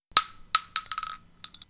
shotgun_shell.wav